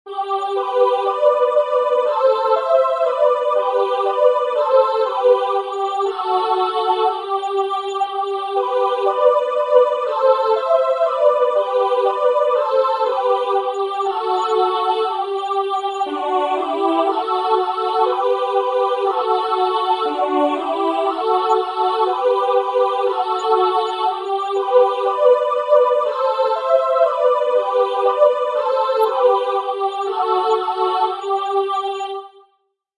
lidová píseň